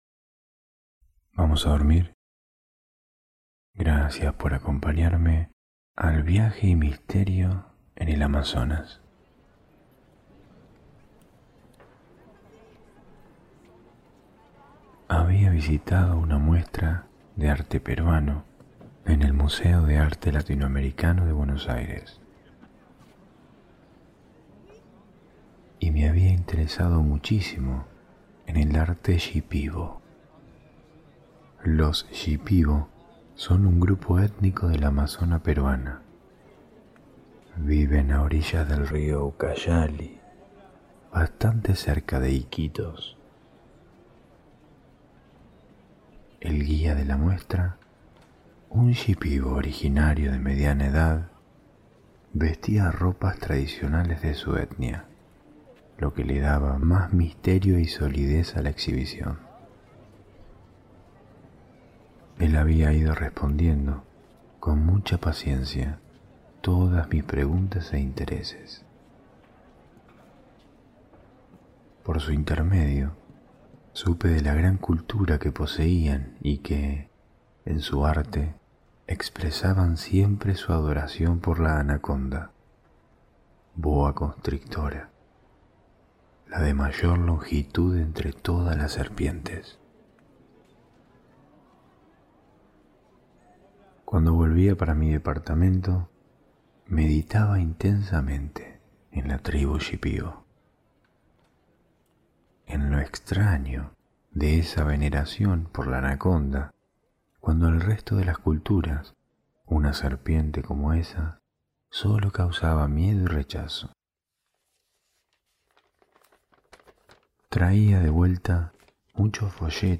Cuentos ASMR para dormir - Viaje y misterio en el Amazonas ✨
Dejate arrullar por el murmullo del Ucayali que corre en busca del Amazonas. Escuchá el canto de los tucanes que atraviesan la floresta. Permití que la cantiga de los chamanes te adormezcan.